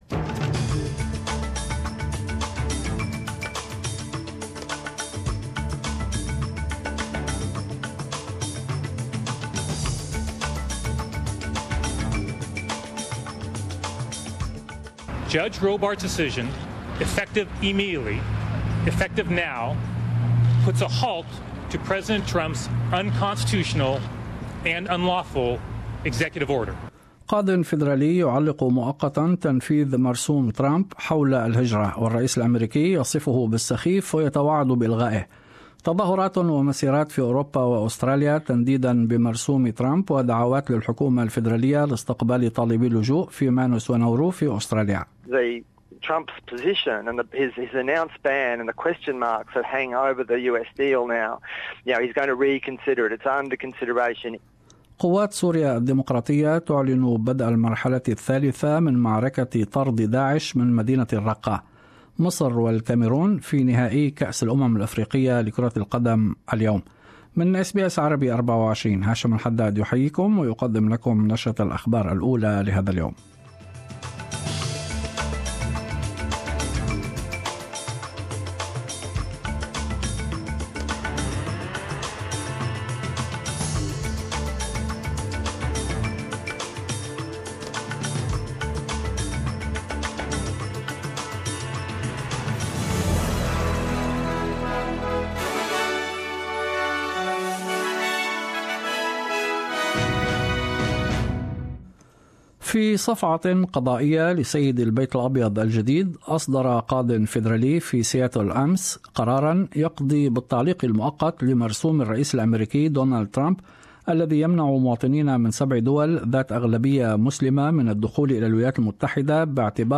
Arabic News Bulletin 5 - 2 -2017